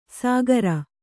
♪ sāgara